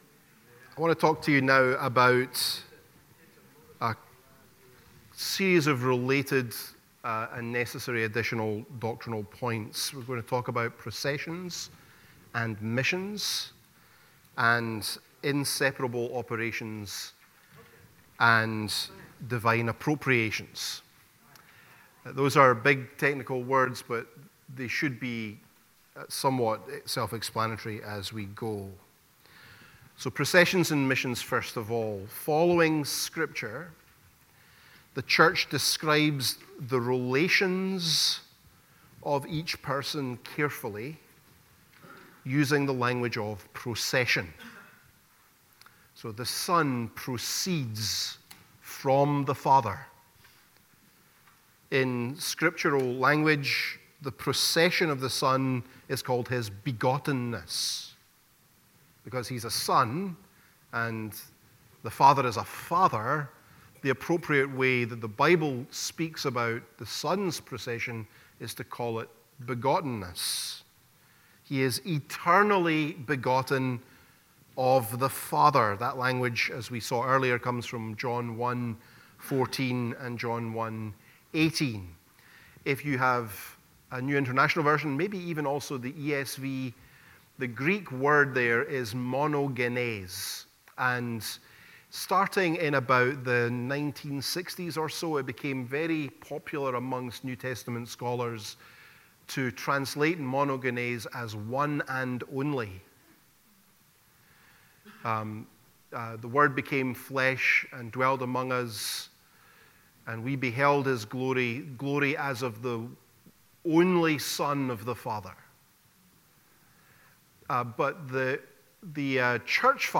The-Doctrine-of-the-Trinity-Lecture-3-Processions-and-Missions.mp3